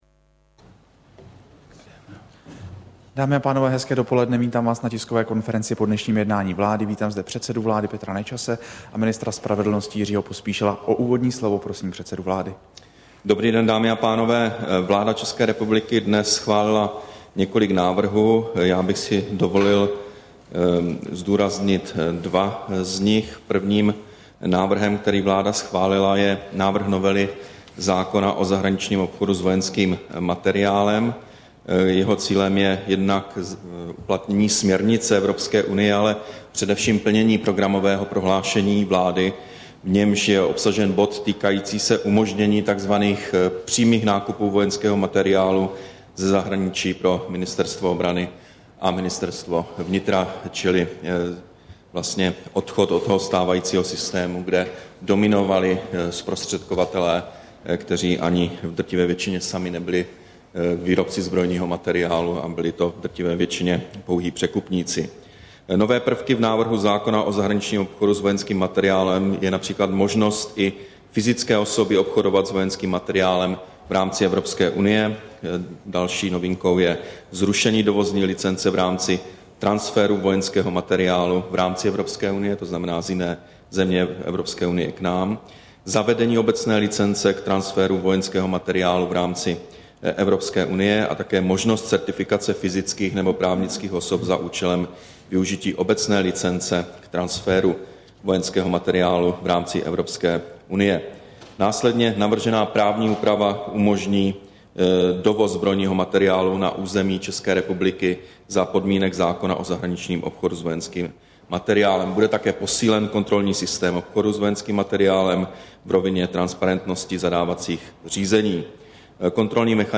Tisková konference po jednání vlády, 30. března 2011